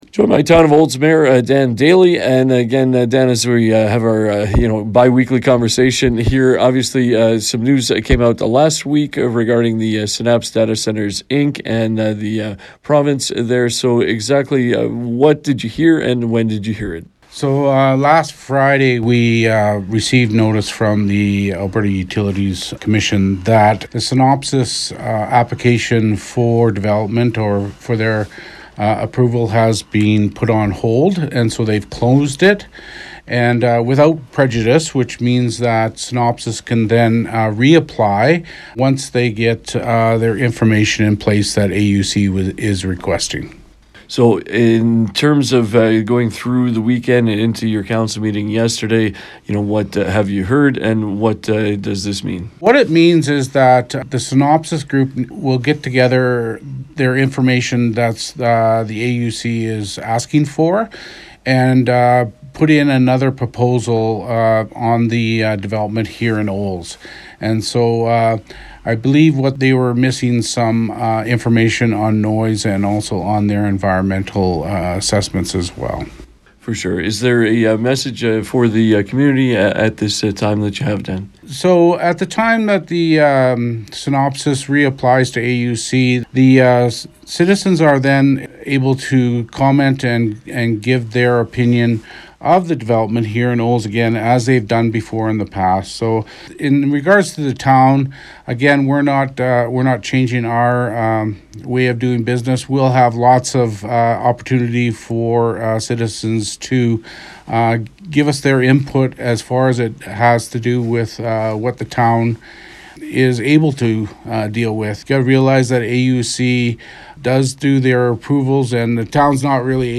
Listen to 96.5 The Ranch’s March 10th conversation with Olds Mayor Dan Daley.
Mar10-Olds-Mayor-Dan-Daley.mp3